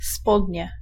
Ääntäminen
IPA: [pɑ̃.ta.lɔ̃]